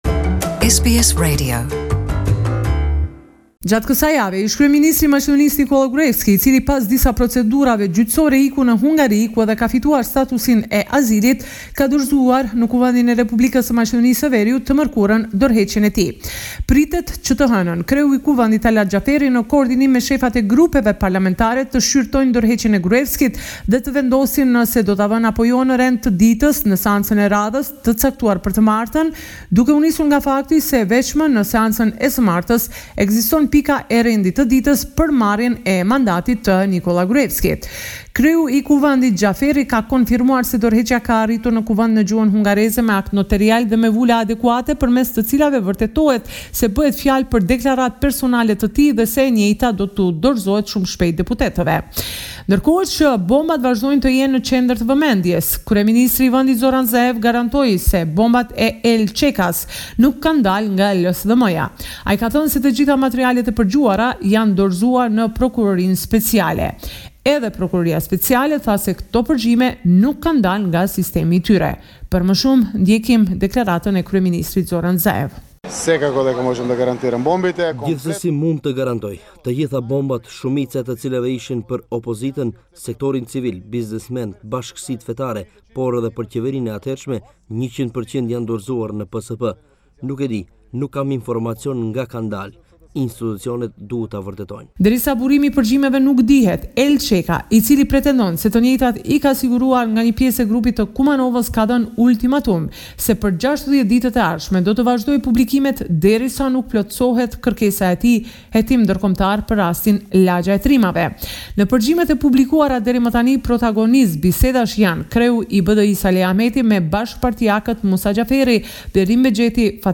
This is a report summarising the latest developments in news and current affairs in North Macedonia